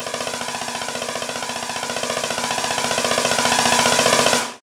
snarefill2.ogg